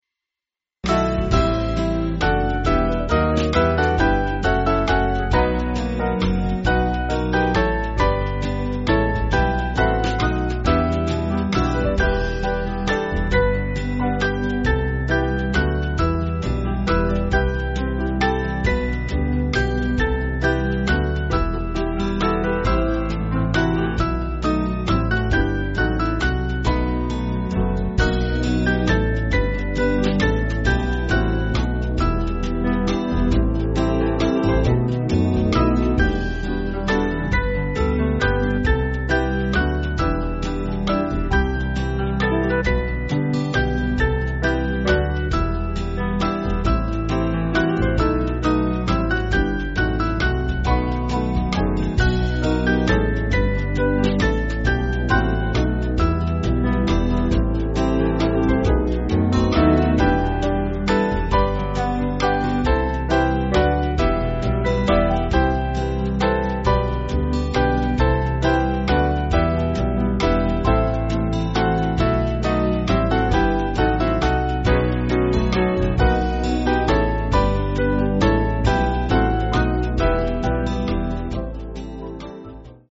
Small Band
(CM)   8/Em-Fm
Alleluia Introduction